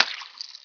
boil_squirt.wav